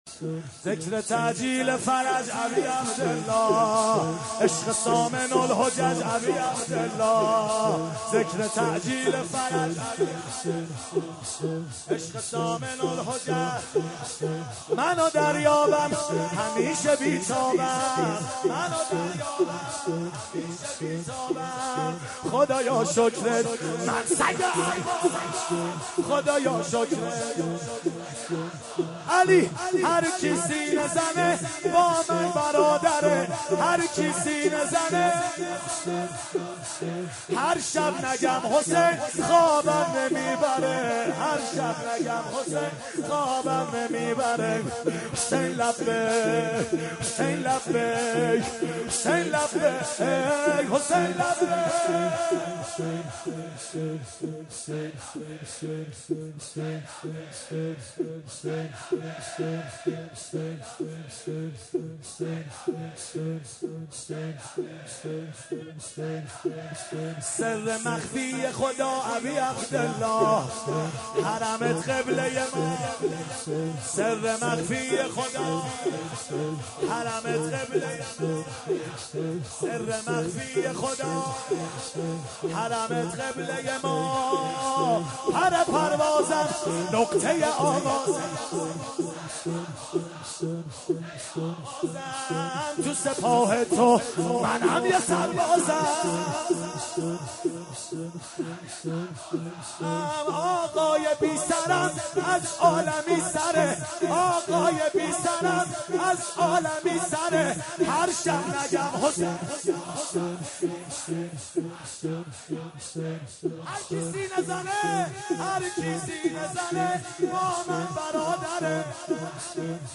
مناسبت : شب هشتم محرم
قالب : شور